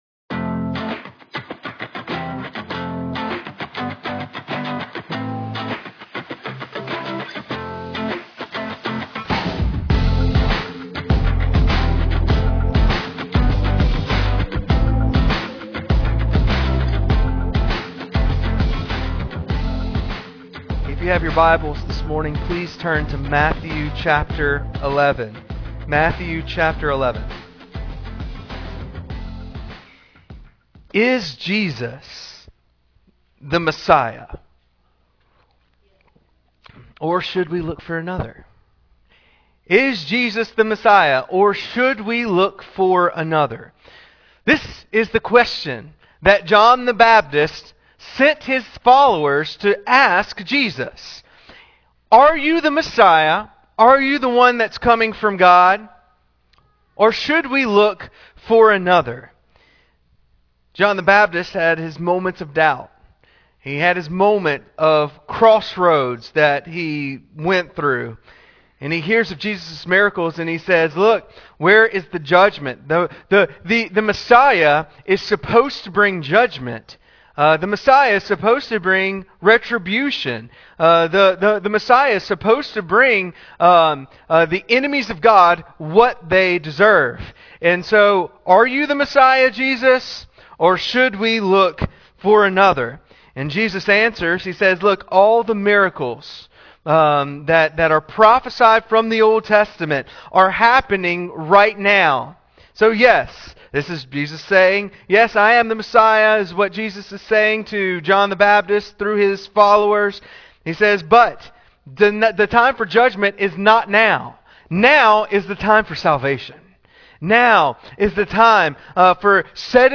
Sermons | Trace Creek Baptist Church